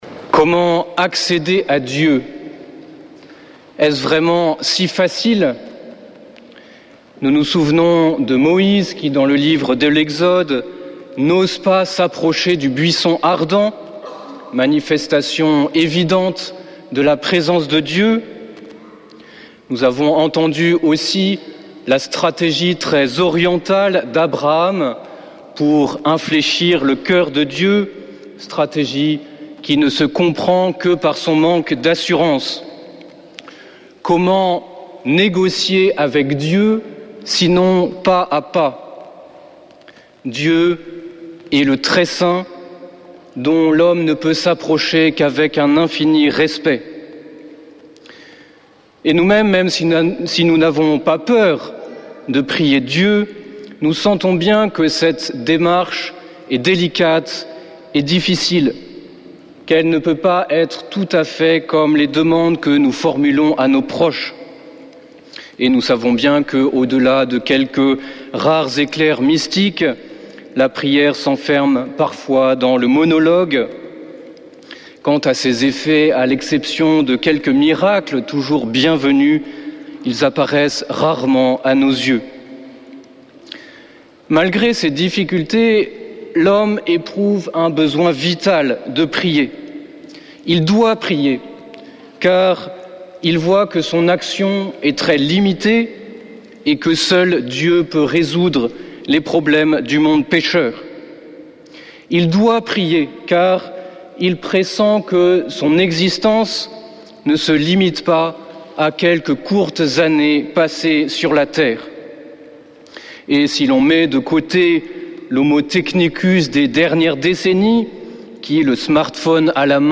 dimanche 27 juillet 2025 Messe depuis le couvent des Dominicains de Toulouse Durée 01 h 30 min